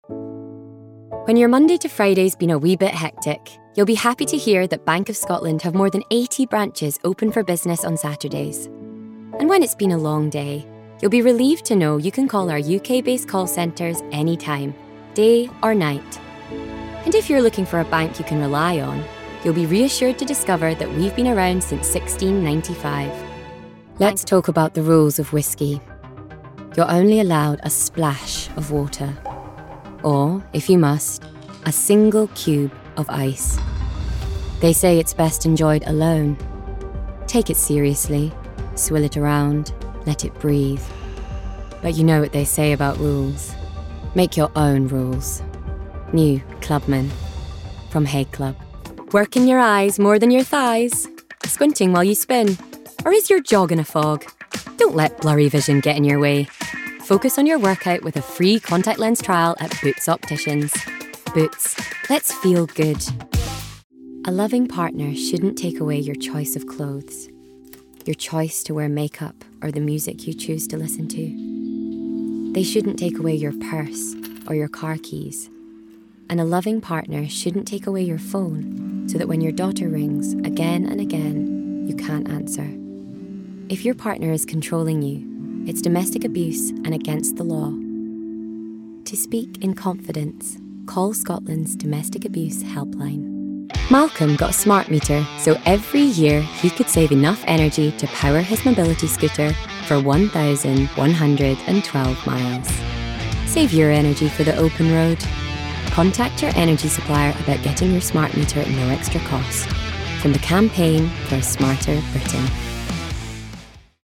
Voice Reel
Commercial Reel